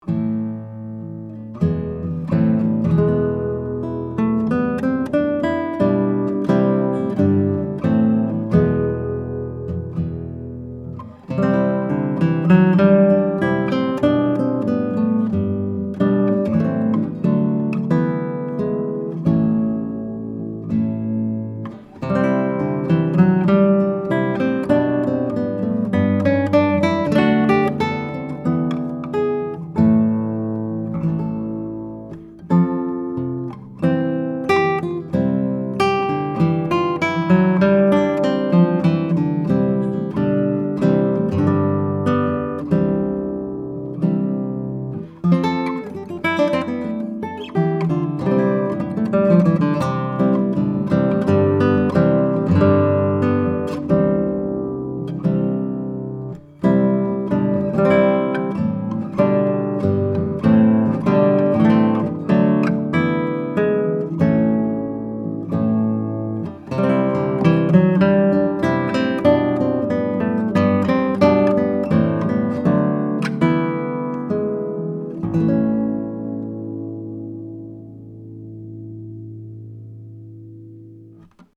Recorded with a couple of prototype TAB Funkenwerk / German Masterworks CG•OA-1 condenser mics into a Trident 88 recording console using Metric Halo ULN8 converters: